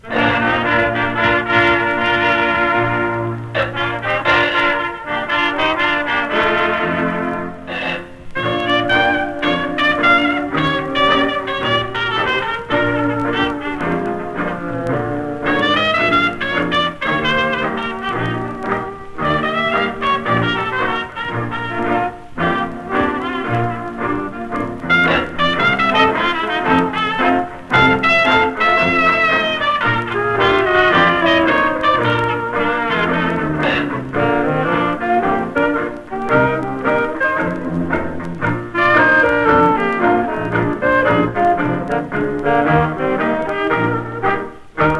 Jazz　USA　12inchレコード　33rpm　Mono